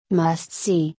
（マストシー）